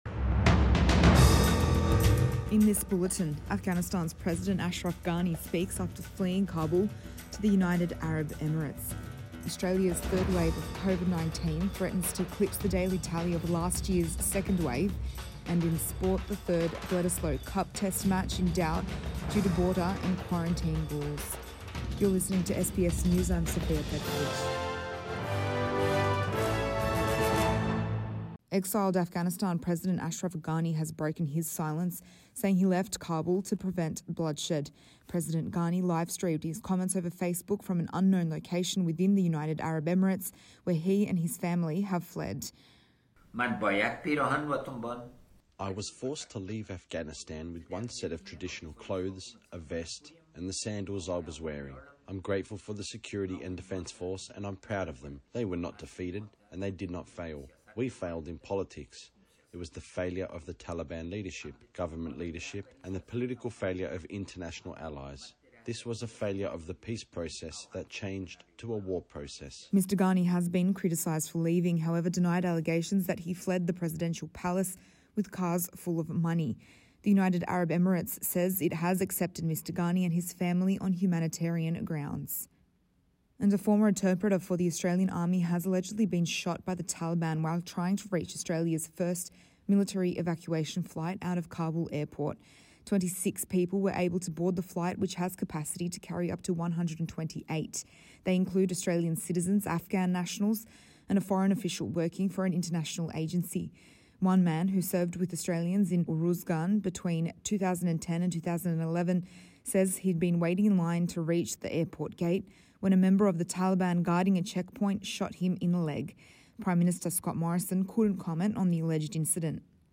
AM Bulletin 19 August 2021